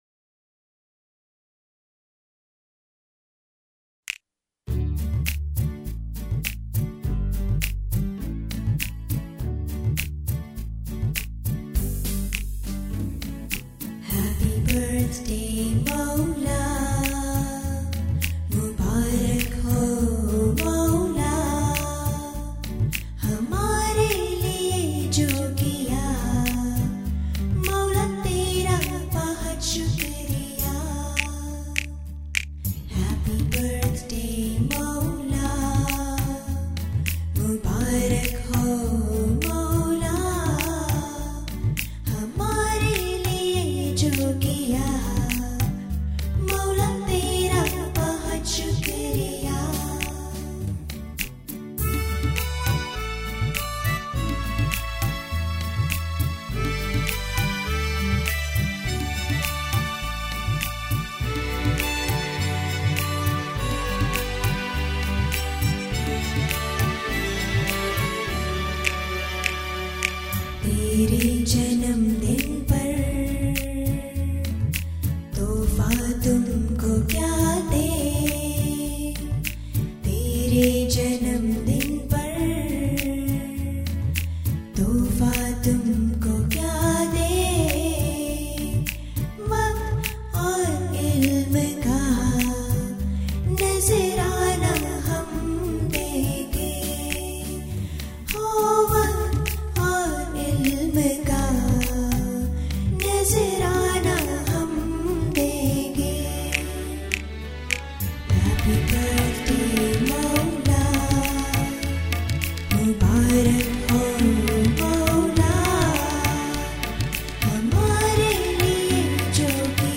35. fabulous & Melodious Nazrana for mowla beautifully